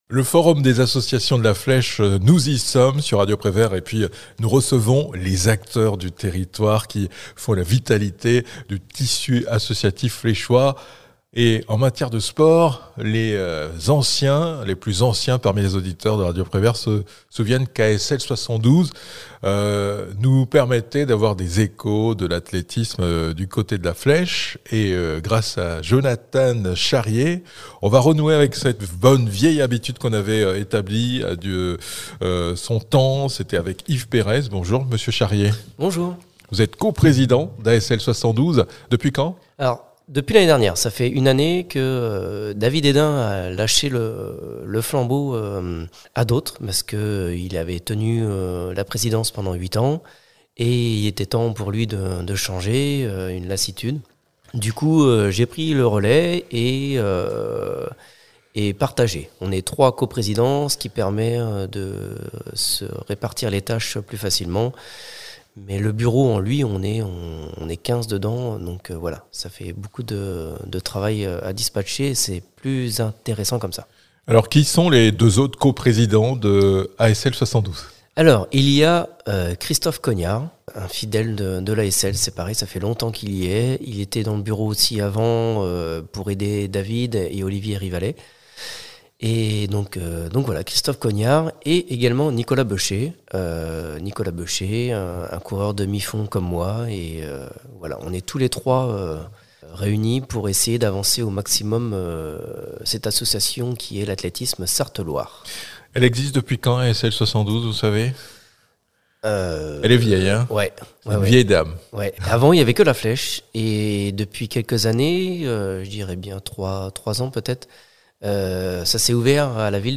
Sports